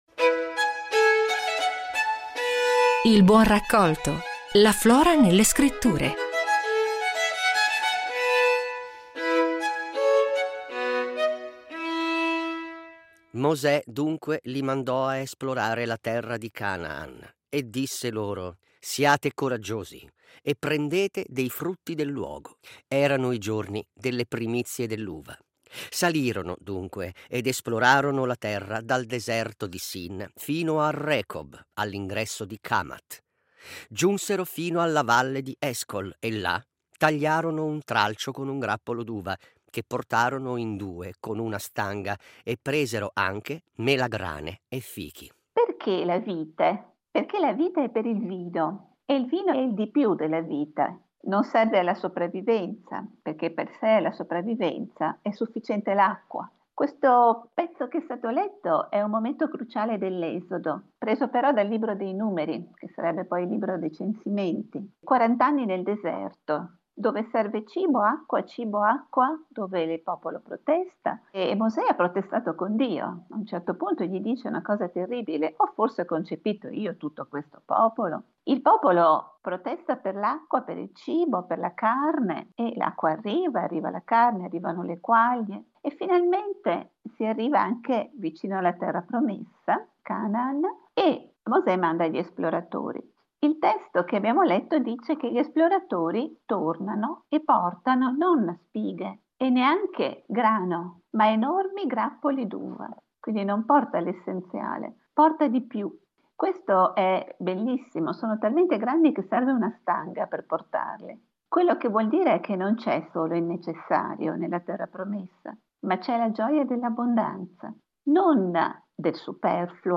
Presentata dalla Professoressa Maria Pia Veladiano